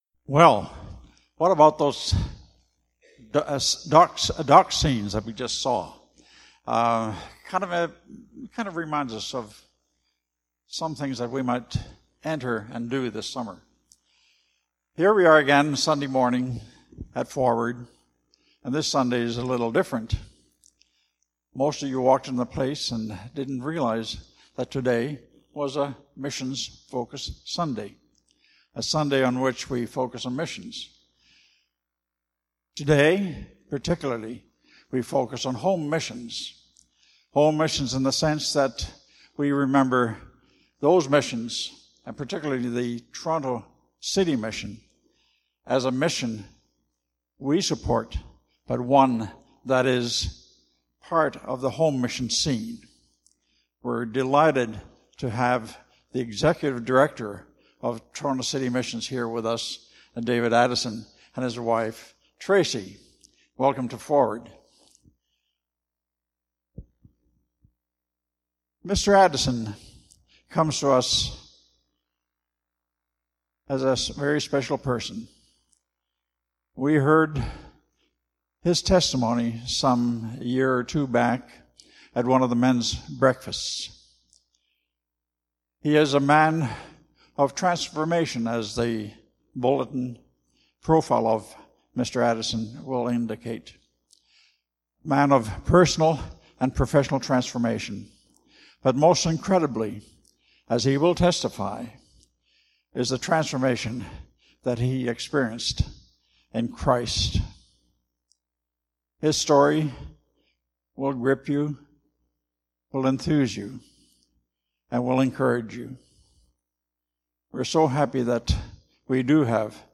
Sermons - Forward Baptist Church, Toronto